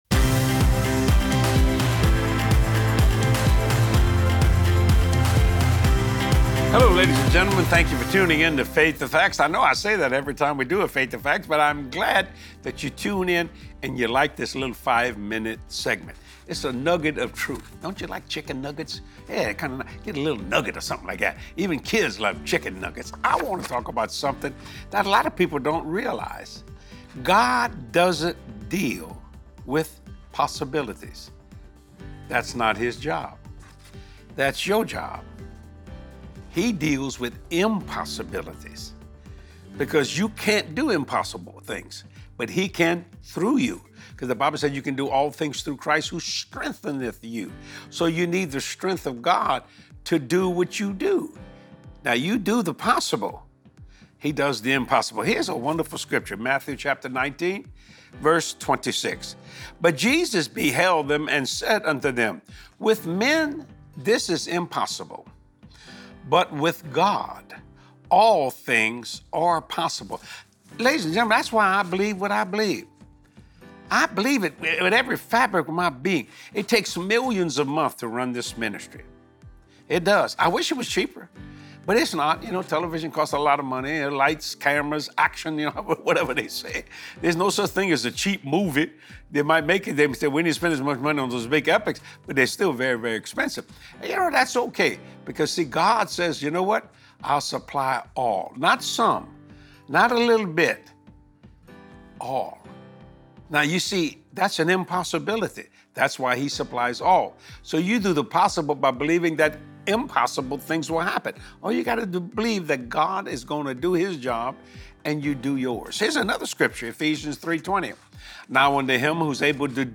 Focus on what you can accomplish in the natural, and then allow GOD to make the impossible…possible! Get charged up in faith as you watch this anointed teaching from Jesse.